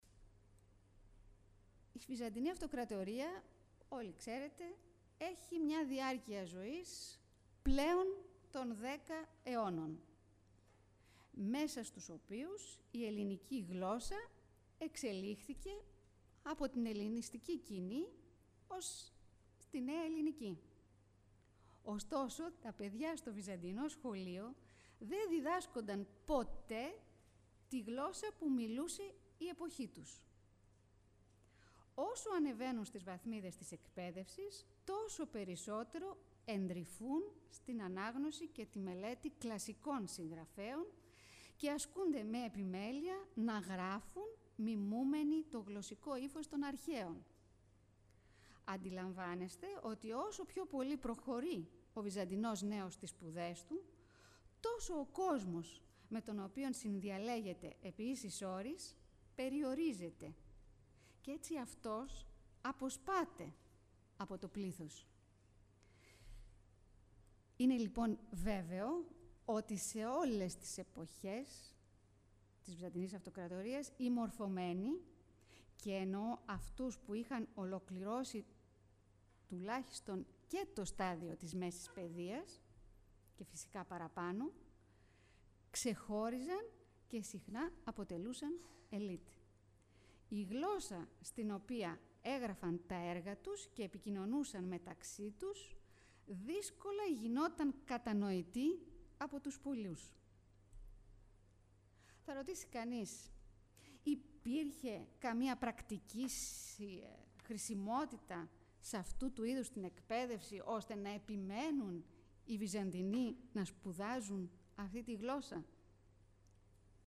9 June 2004 at the chamber Theotokopoulos two lectures with the subject: «Schools and education in Constantinople during the Komninoi period»